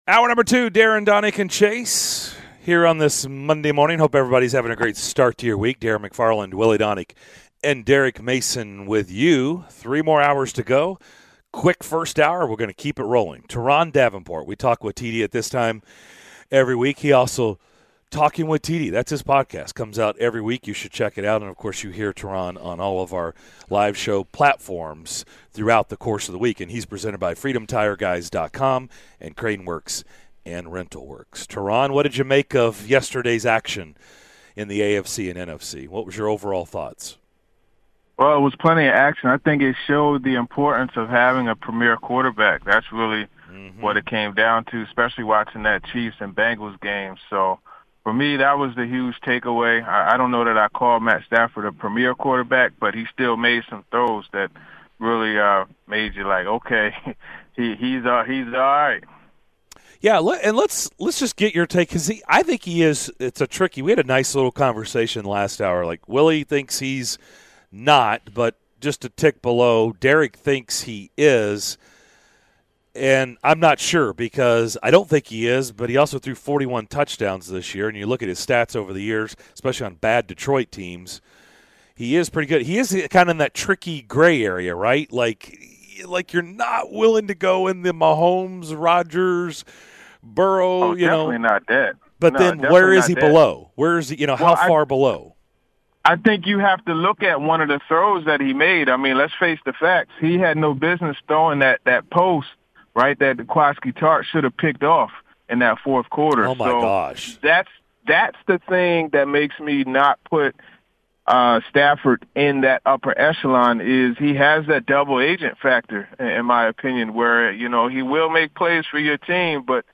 the callers weigh in on "This Is What I Know" and more!x